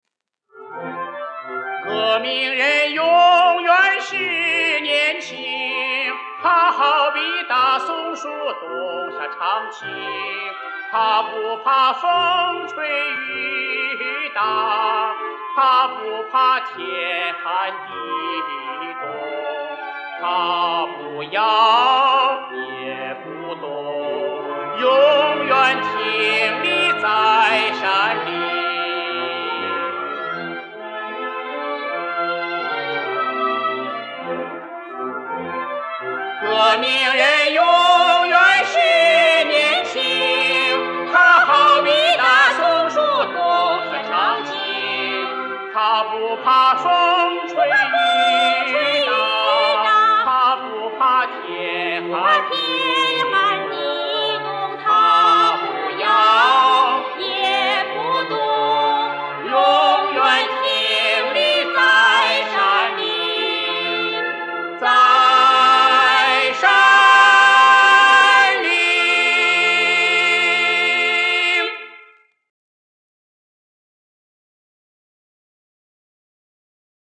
这是两人的二重唱。
50年代录音